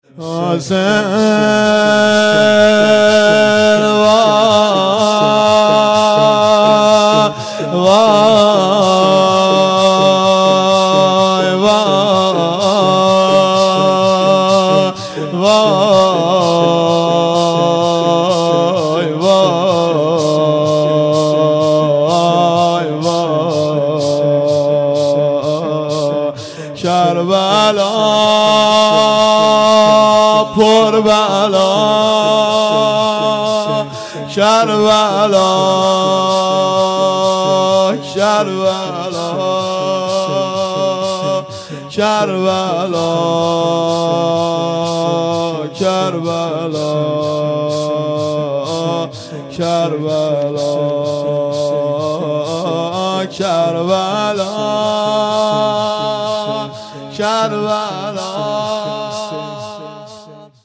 صوت های مراسم ظهر عاشورا محرم الحرام 95
ذکر و نوا